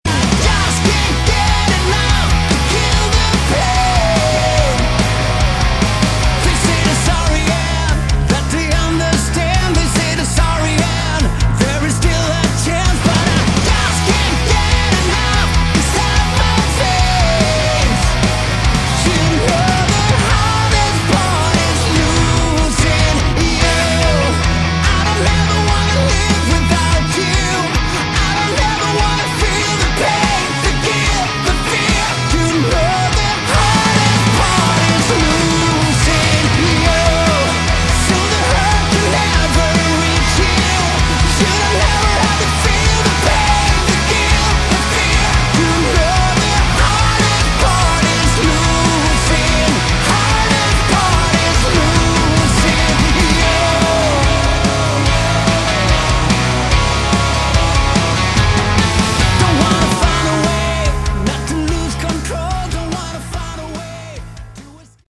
Category: Melodic Hard Rock
vocals
guitars
drums
bass